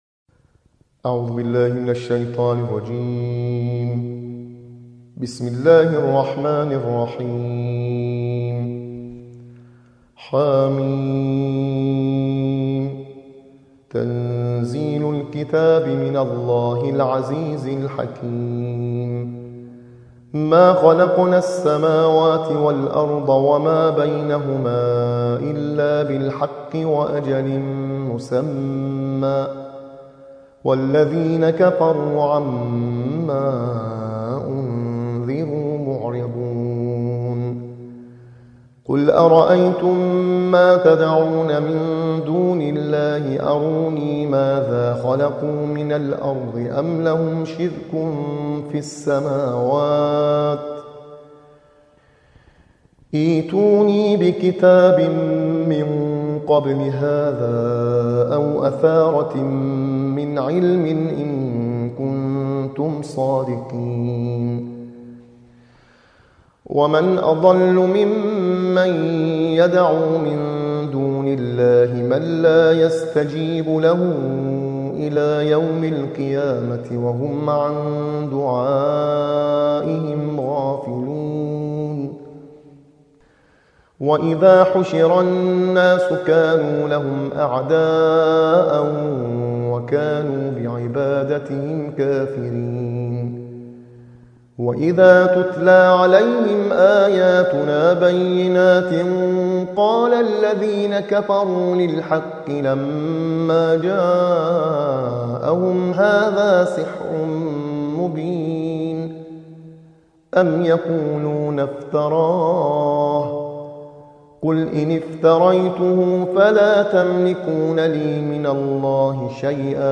ترتیل‌خوانی جزء ۲۶ قرآن